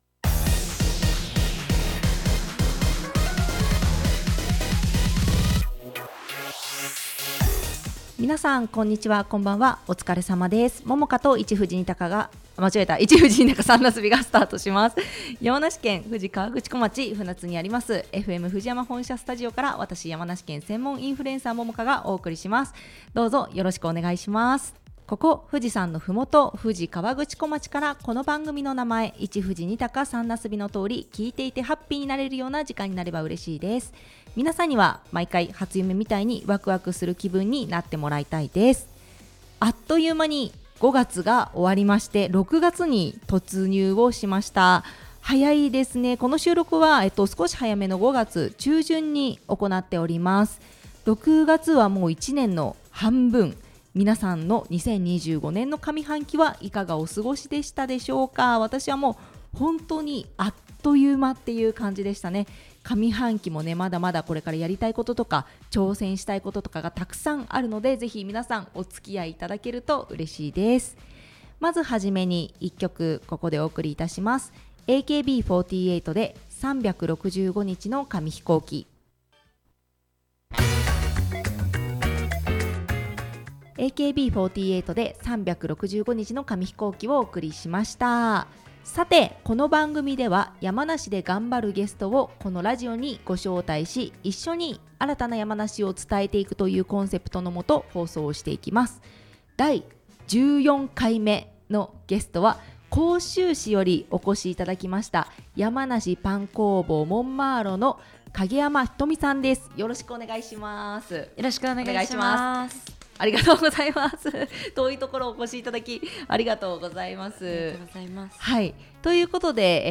（ネット配信の為楽曲はカットしています）